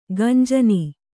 ♪ gañjani